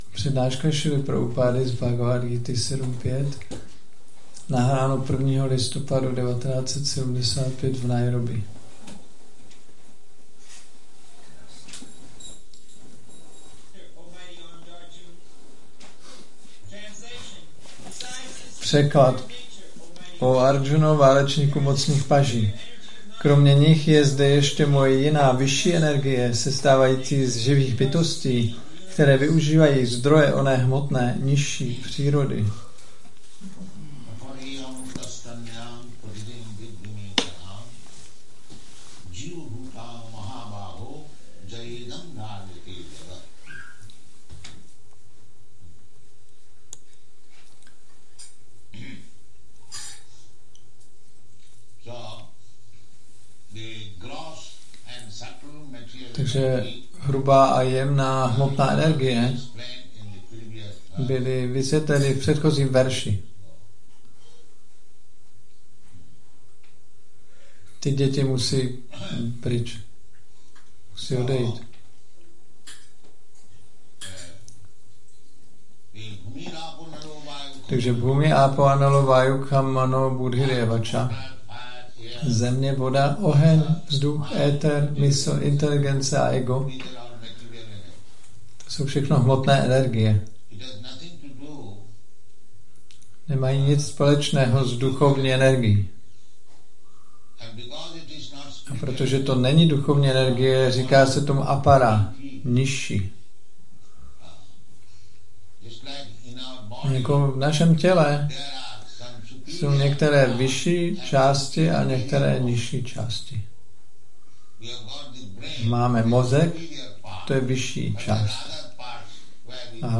1975-12-01-ACPP Šríla Prabhupáda – Přednáška BG-7.5 Nairobi